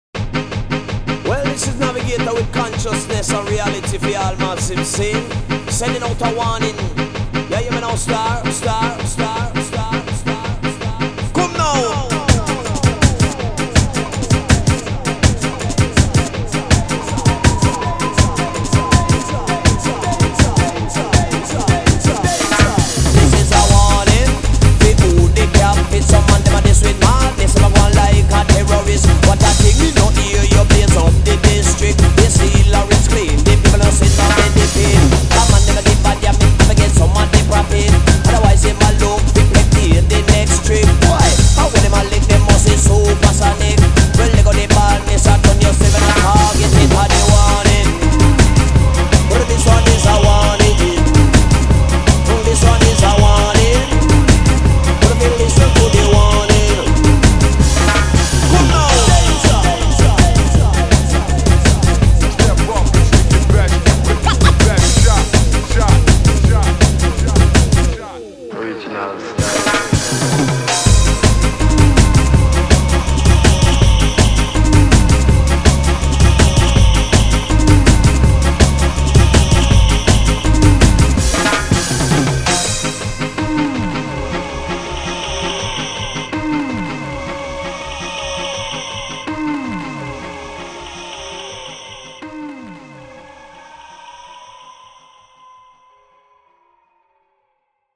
BPM81-162
Audio QualityMusic Cut
An interesting ska-like remix